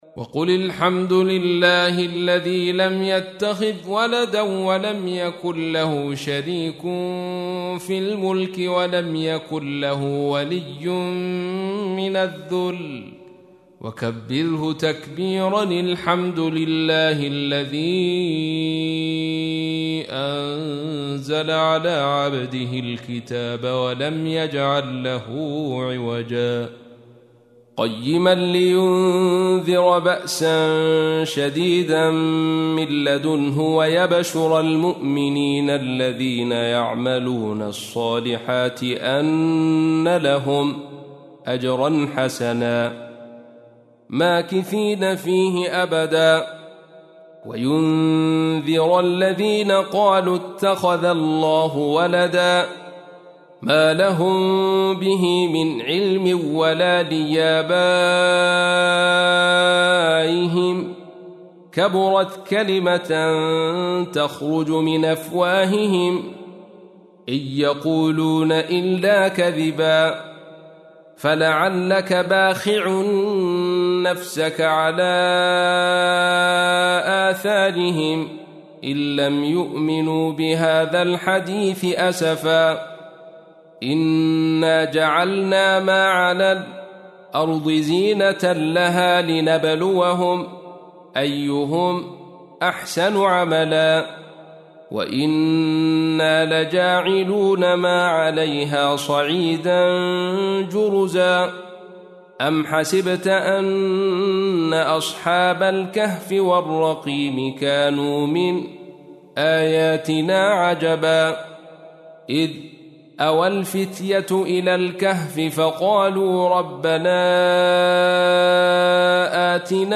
تحميل : 18. سورة الكهف / القارئ عبد الرشيد صوفي / القرآن الكريم / موقع يا حسين